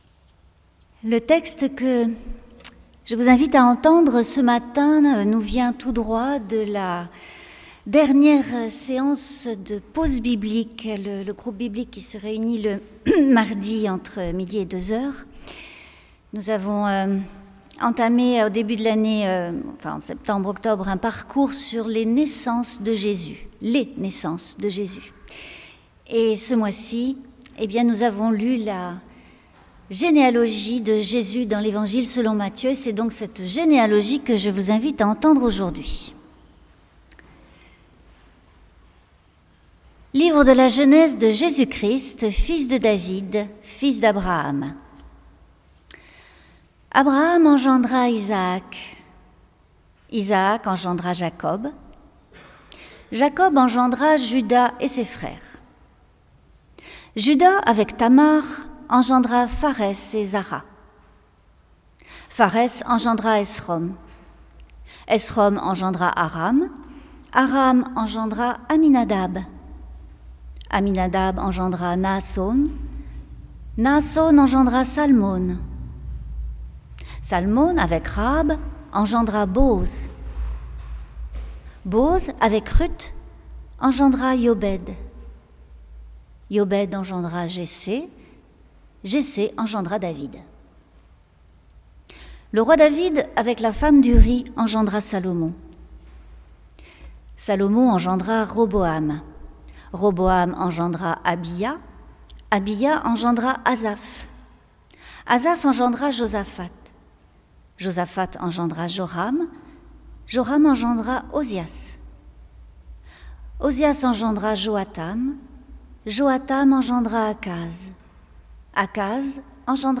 Prédication : Le retour, le repos, le calme et la confiance